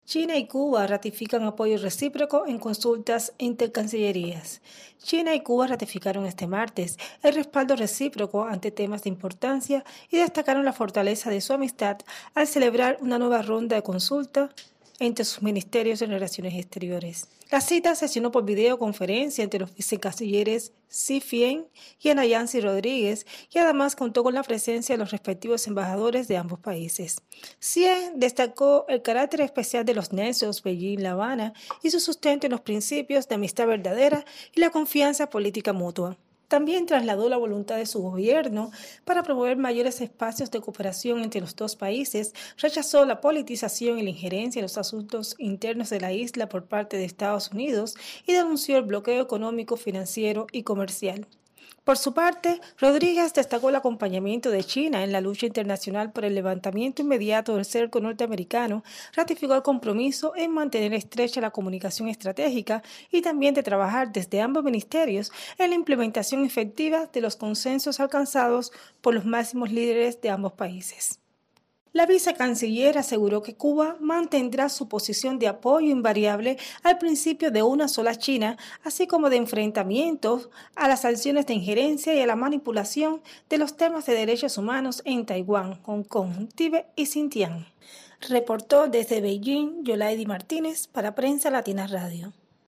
desde Beijing